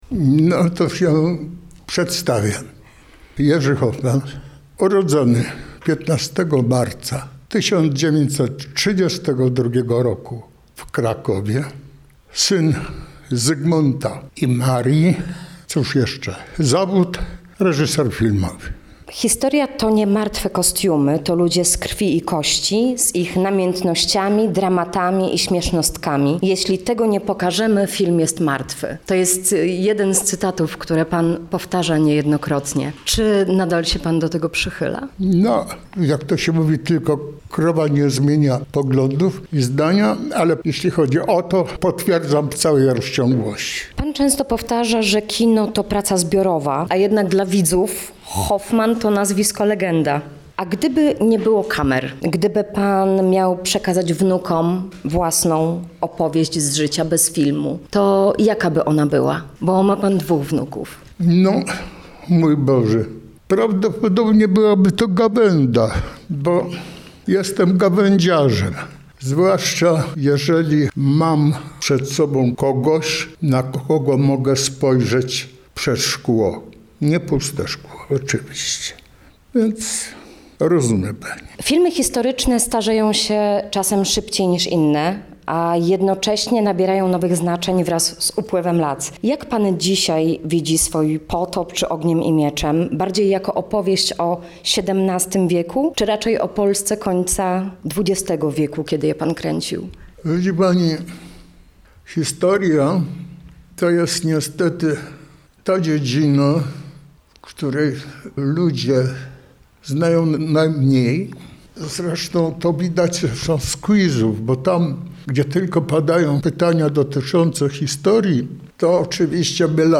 Hoffman-wywiad-rzeka.mp3